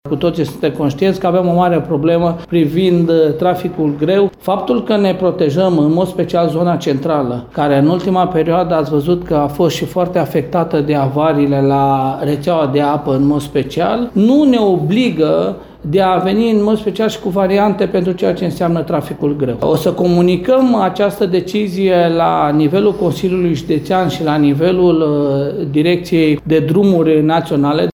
Măsura a fost luată, după discuții cu Poliția Rutieră, Poliția Națională și Poliția Locală, spune primarul Călin Dobra.